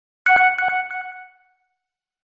messagealert3.mp3